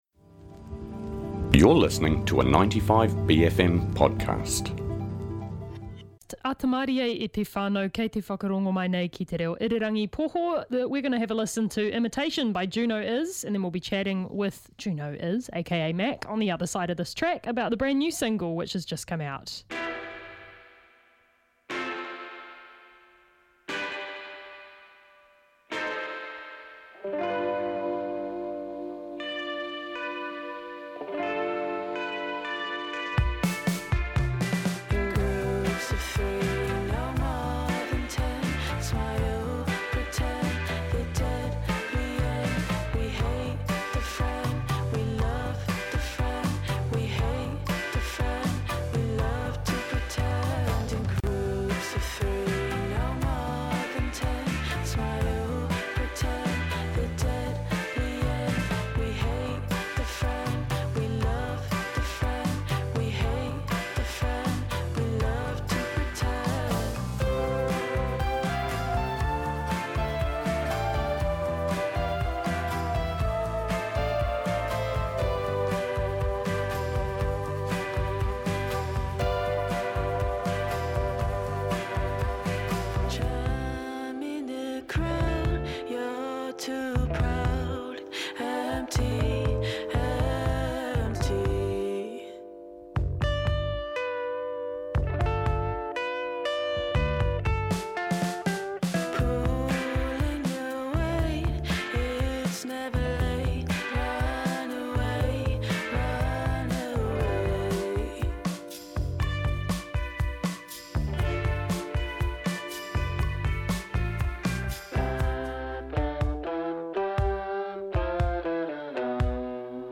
Guest Interviews
A simple feed of all the interviews from our many and varied special bFM Breakfast guests.